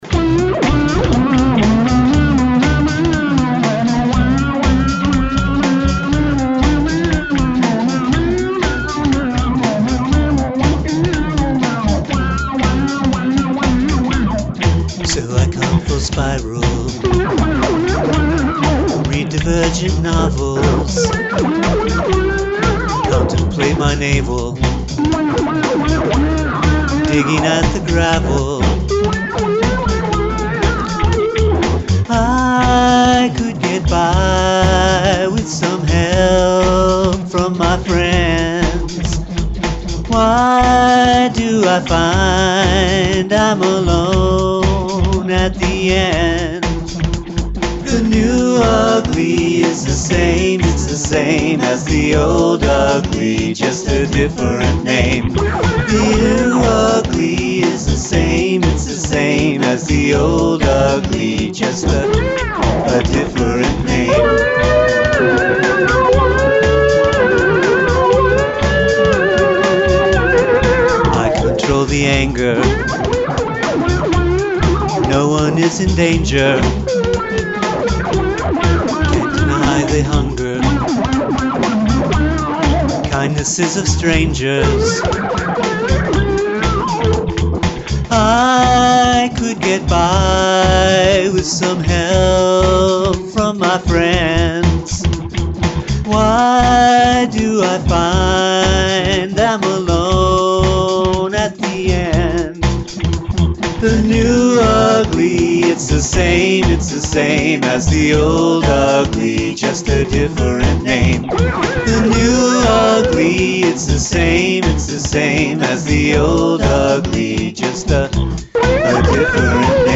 Only Percussion and Voice (no pianos allowed)